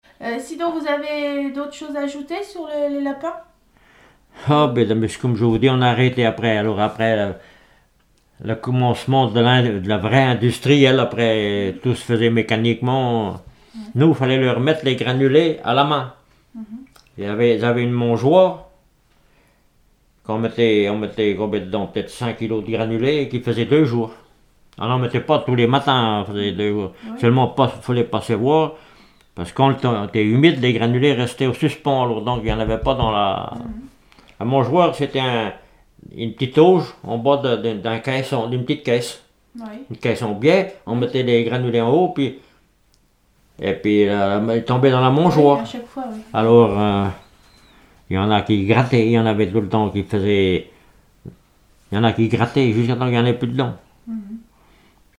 Enquête Arexcpo en Vendée-GAL Pays Sud-Vendée
Catégorie Témoignage